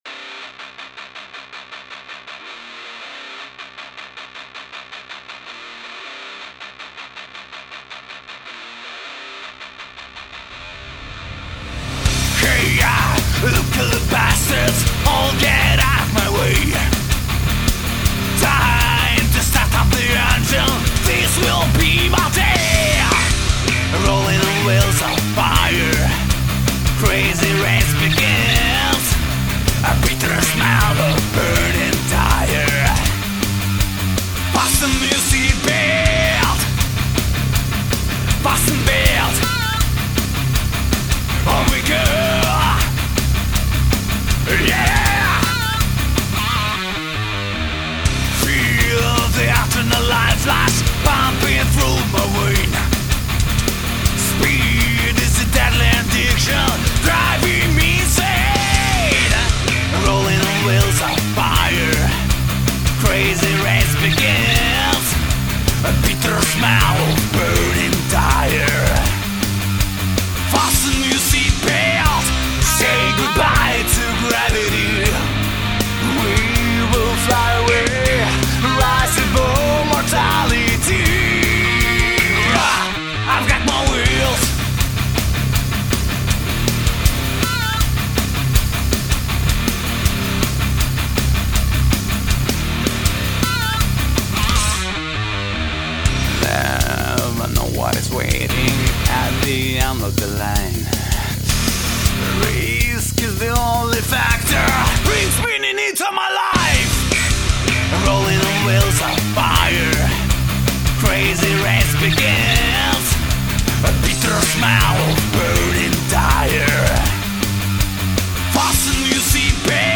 Интересно , на нем ктото может нарулить риффовый звук круче LINE6 ? в плане тембра MesaBoogie Dual Rectifier + 4x12 Modern Cab Я пытался сделать с ходу, но пока чтото все равно жужжит в нем...